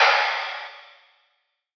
Gamer World Open Hat 10.wav